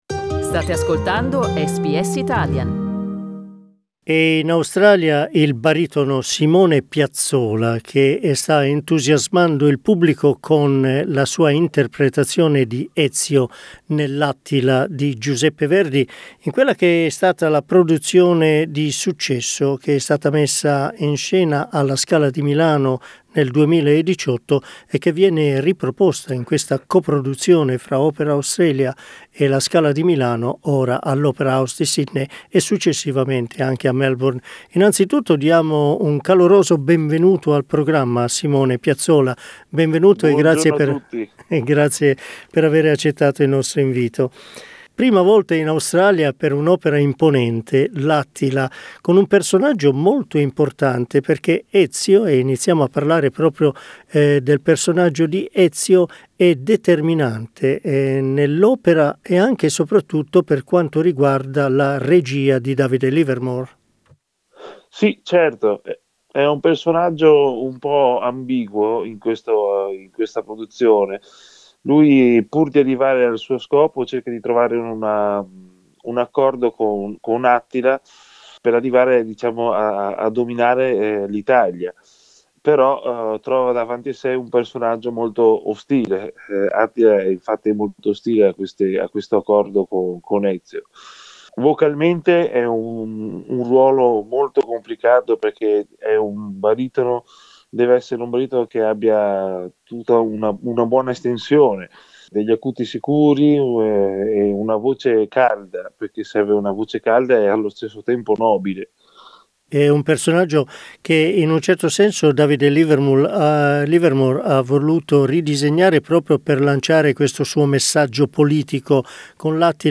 In this interview, the Italian singer talks about his career and his future plans.